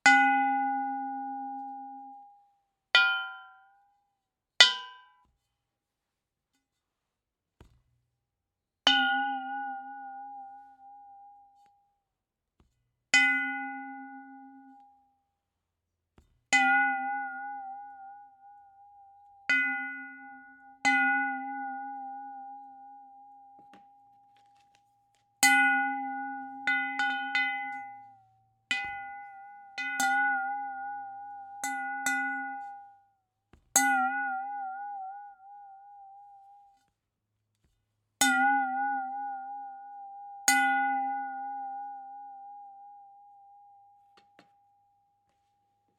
Steel Water Bowl Hits
bell boing bowl clang ding hit metal metallic sound effect free sound royalty free Nature